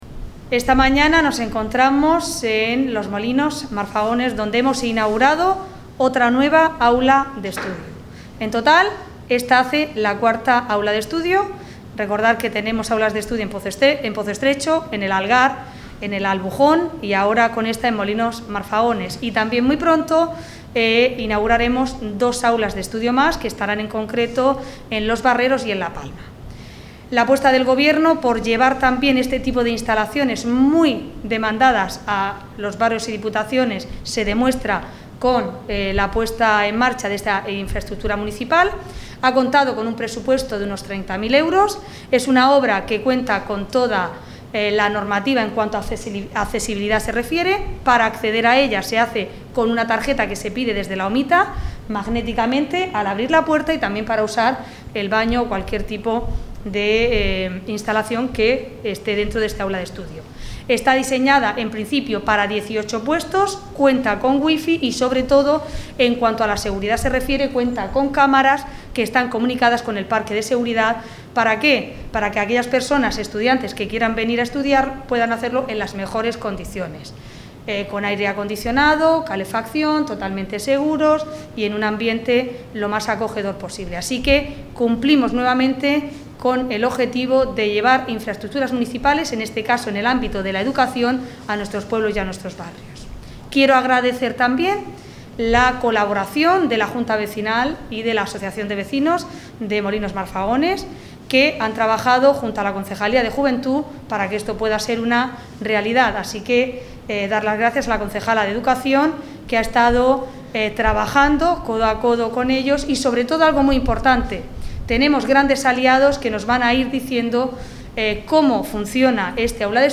La alcaldesa, Ana Belén Castejón, y la concejala de Educación, Irene Ruiz, han inaugurado esta mañana el nuevo Aula de Estudios de Molinos Marfagones.